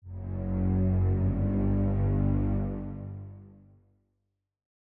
Нагоняющие страх звуки для монтажа видео и просто испугаться слушать онлайн и скачать бесплатно.